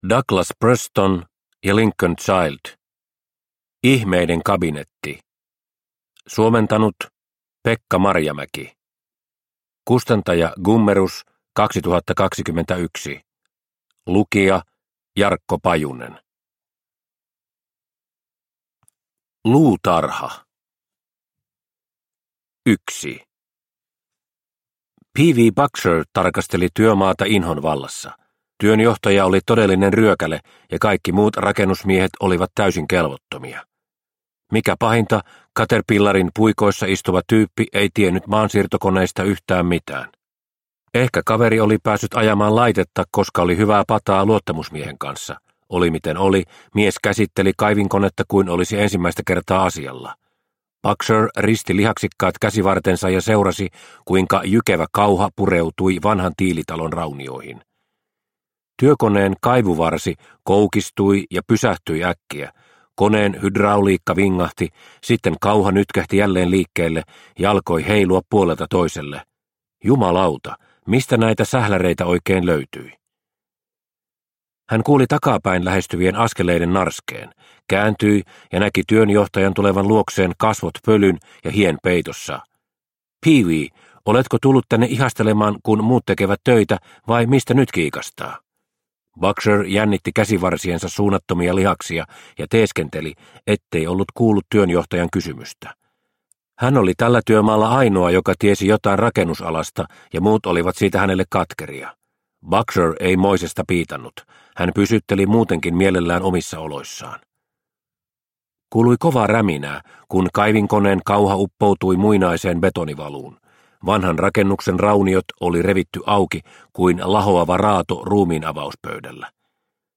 Ihmeiden kabinetti – Ljudbok – Laddas ner